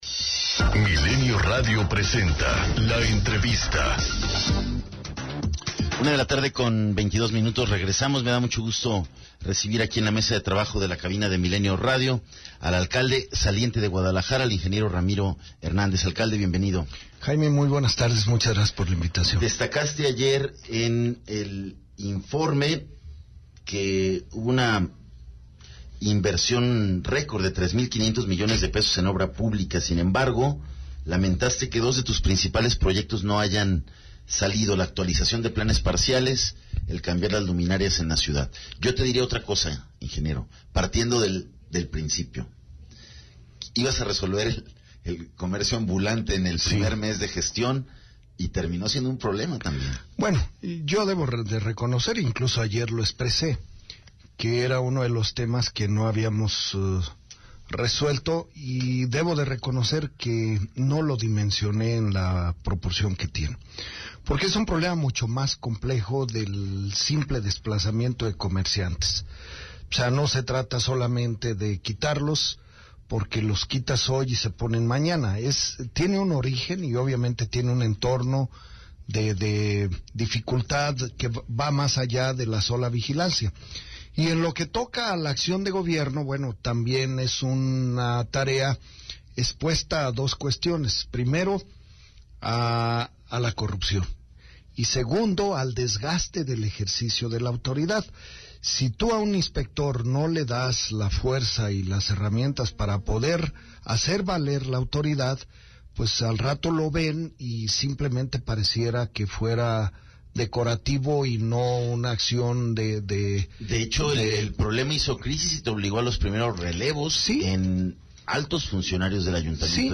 ENTREVISTA 110915
El alcalde saliente de Guadalajara Ramiro Hernández afirmó en entrevista para Milenio Radio que que dentro de su gestión uno de los mayores aciertos fue la renovación de infraestructura así como la pavimentación de 169 kilómetros lineales demás del fortalecimiento de la policía municipal, reconoció que en el tema del ambulantaje no se lograron las metas planteadas por qué no dimensionó el problema de dicho sector.